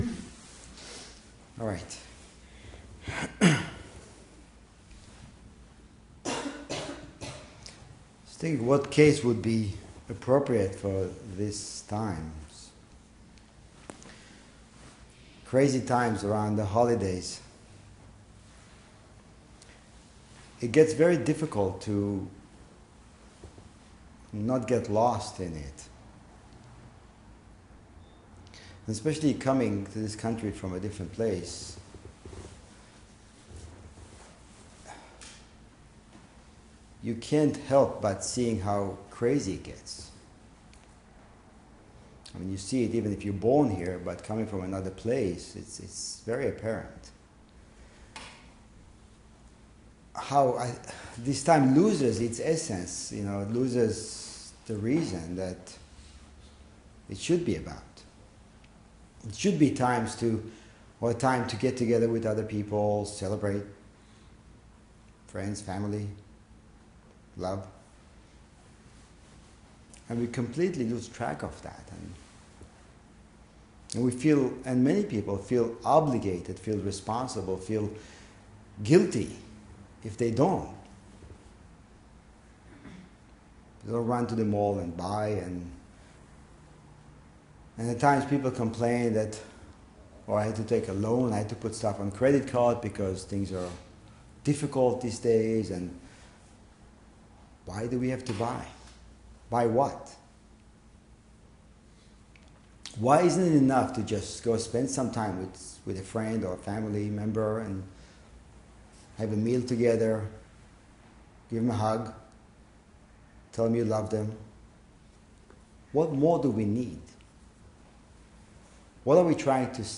Dharma Talks | Aikido of Ramapo Valley